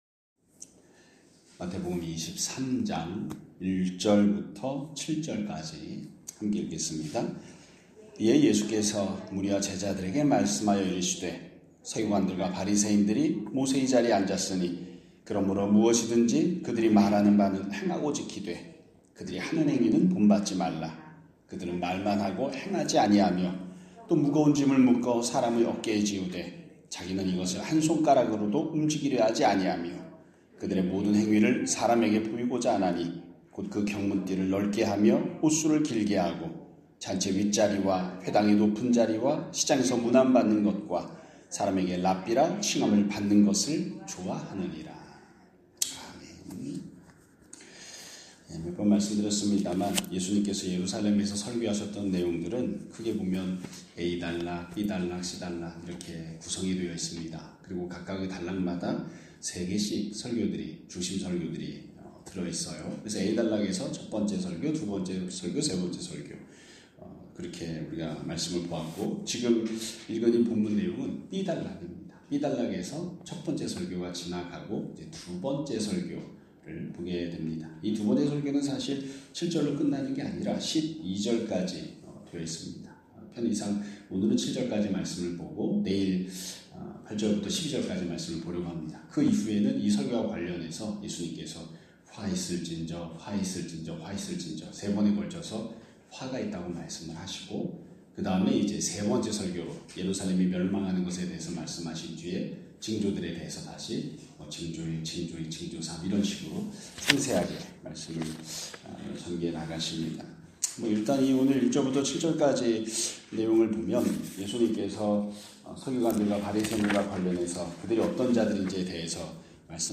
2026년 2월 19일 (목요일) <아침예배> 설교입니다.